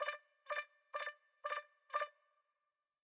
Standardowy dźwięk złej odpowiedzi, typowy dla gier.